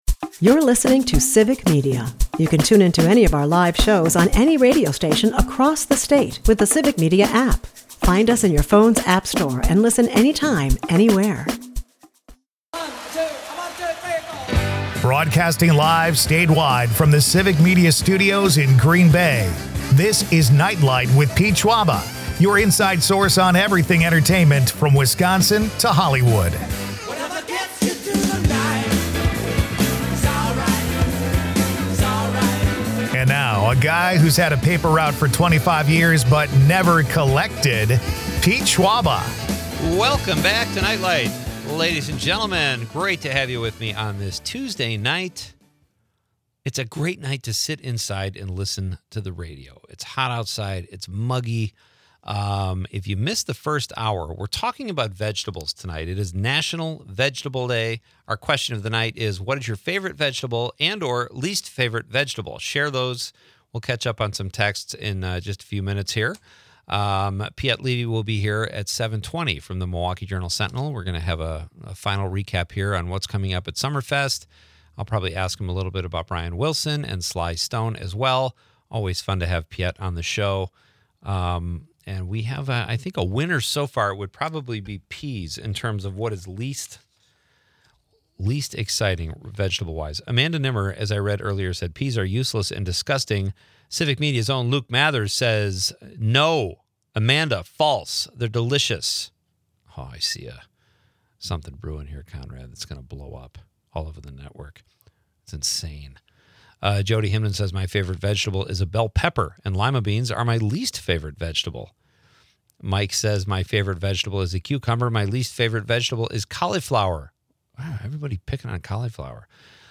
is a part of the Civic Media radio network and airs Monday through Friday from 6-8 pm across Wisconsin.